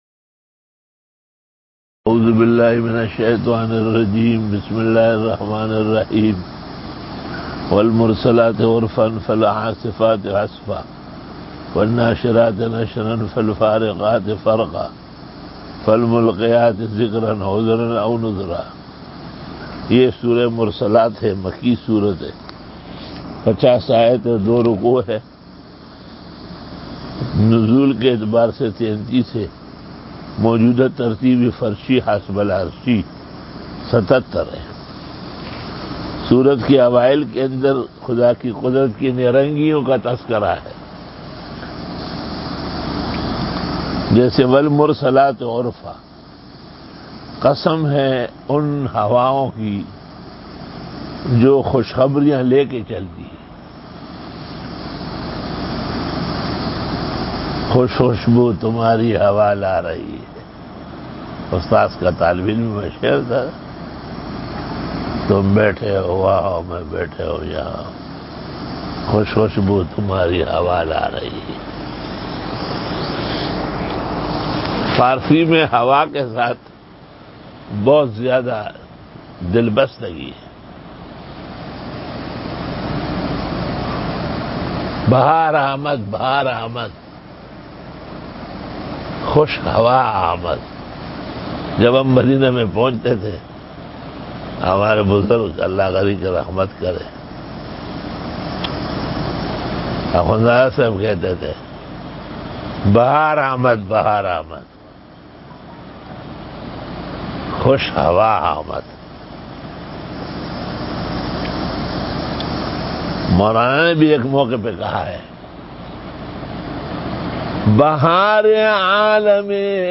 93 Quran Tafseer 22 July 2020 Surah Mursalaat _( 30 Zil Qaadah 1441 H) - Wednesday Day 93